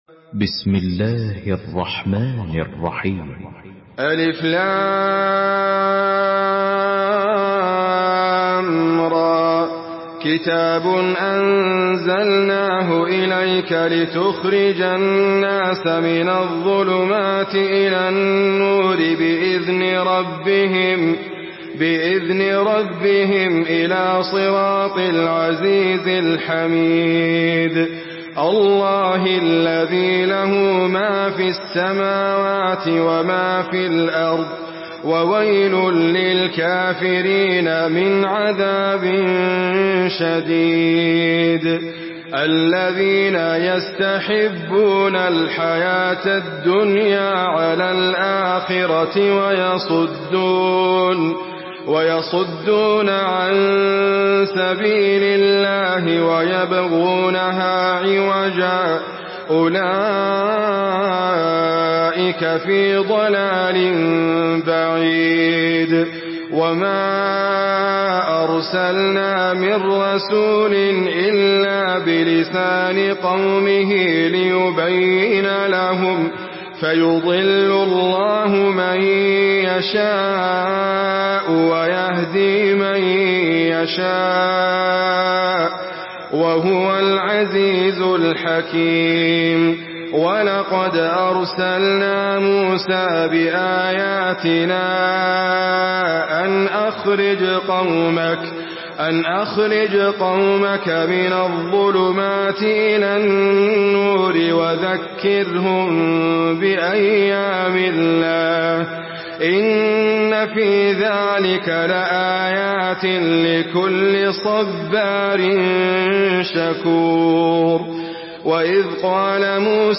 Surah إبراهيم MP3 by إدريس أبكر in حفص عن عاصم narration.
مرتل حفص عن عاصم